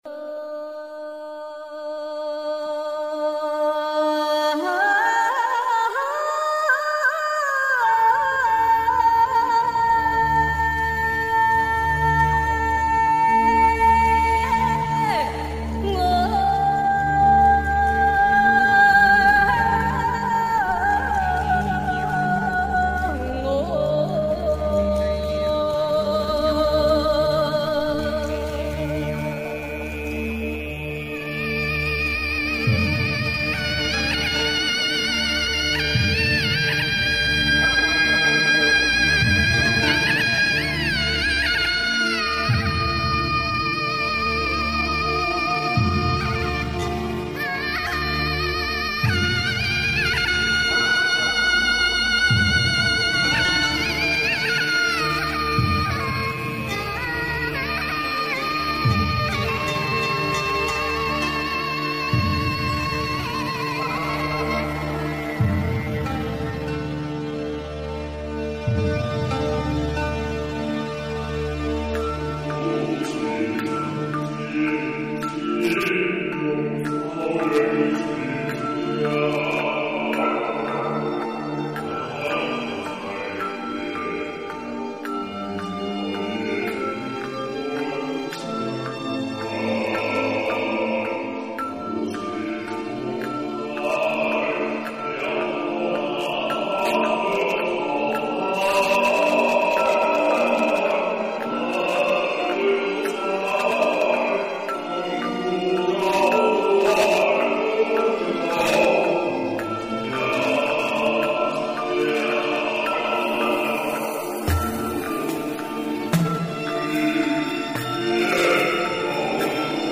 其次，歌的旋律听上去就象蒙古草原上悲壮地马蹄声滚滚而来，一浪高过一浪，不屈不挠。
引子中的伴唱是伴奏中自带